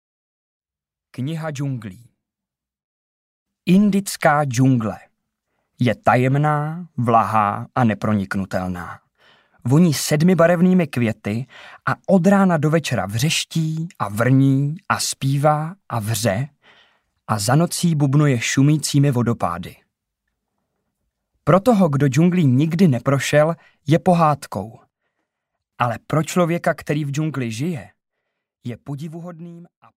Disney - Kniha džunglí audiokniha
Ukázka z knihy